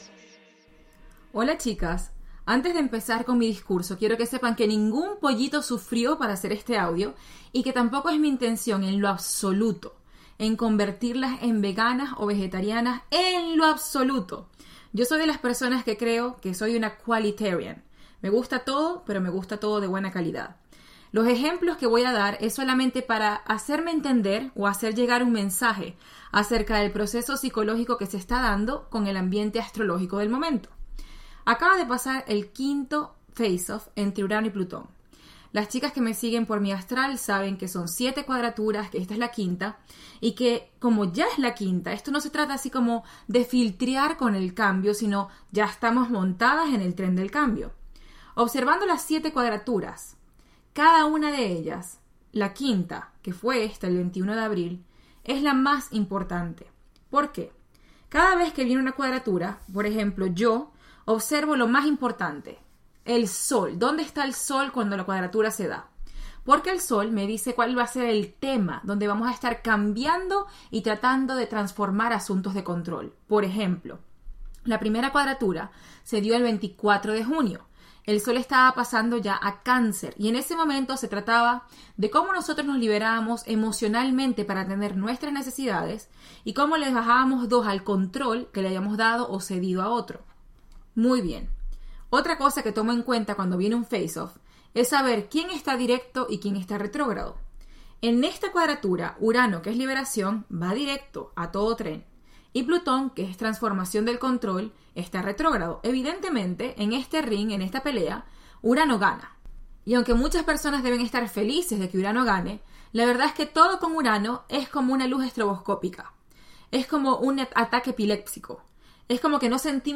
¿Cómo trabajar en tu autoestima? Clase de coaching